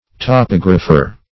Topographer \To*pog"ra*pher\, n. [Cf. F. topographe, Cr.